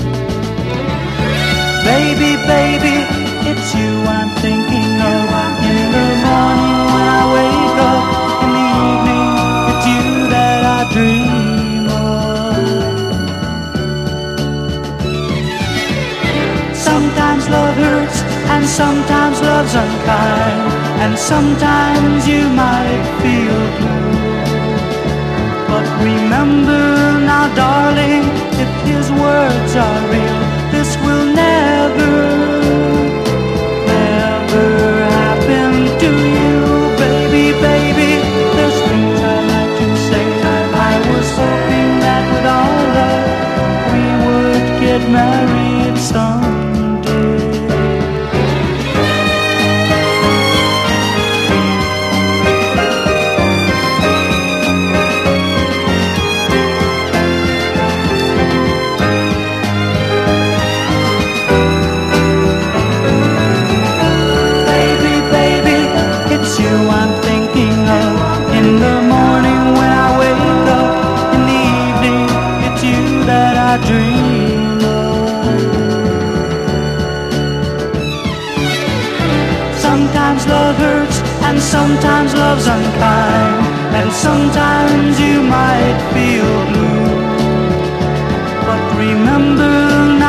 柔らかくまどろんだコーラス/ハーモニーに溢れたシカゴ・ガレージ・ソフトロック！